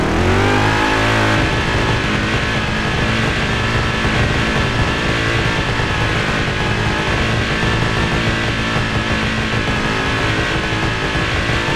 Index of /server/sound/vehicles/lwcars/buggy
rev.wav